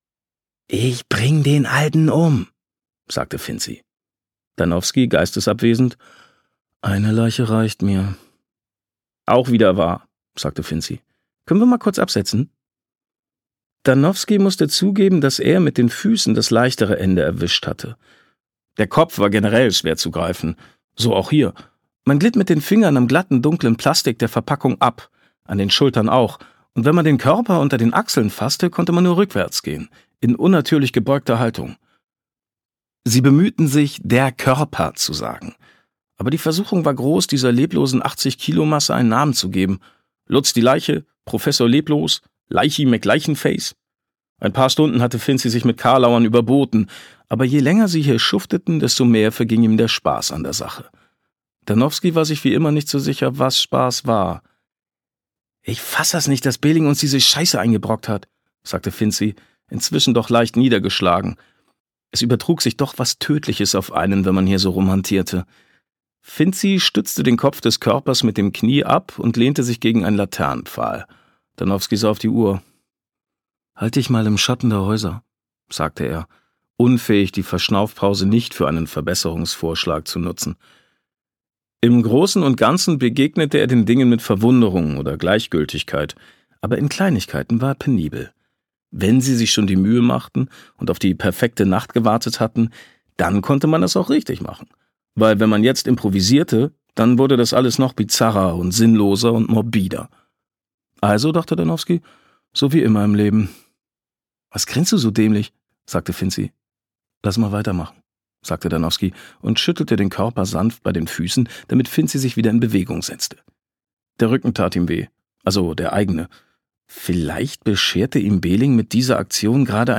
Gekürzt Autorisierte, d.h. von Autor:innen und / oder Verlagen freigegebene, bearbeitete Fassung.
Hörbuchcover von Danowski: Hausbruch